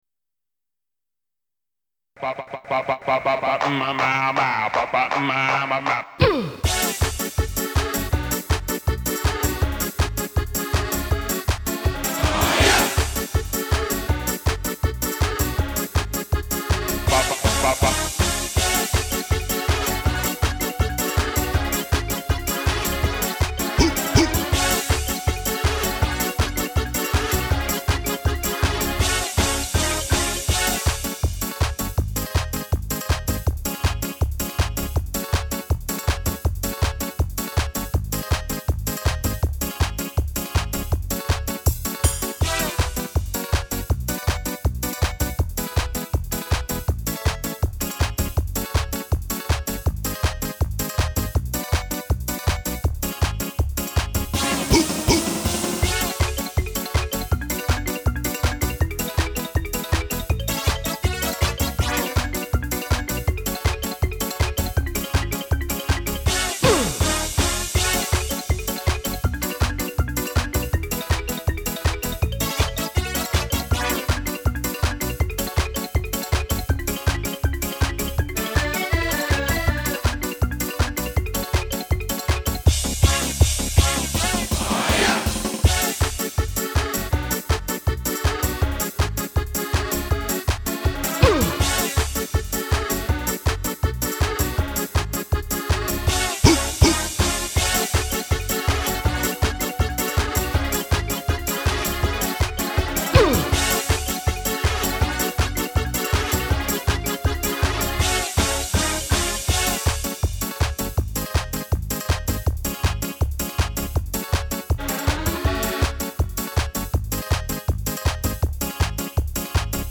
То ж самое, только в минусовом варианте